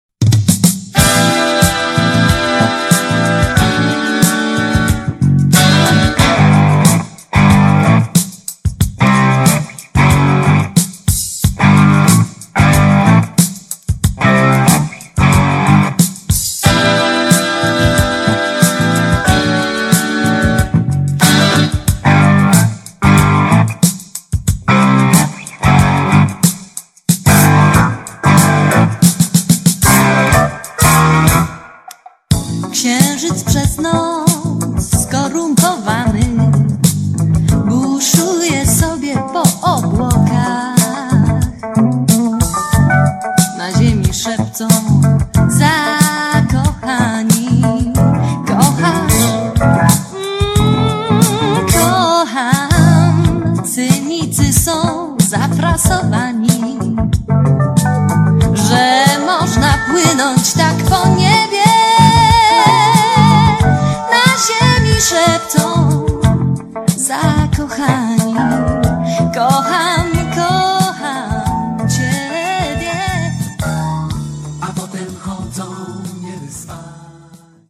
urodzona w Nowym Sączu  piosenkarka, kompozytorka.
Równolegle ze swoją główną twórczością pop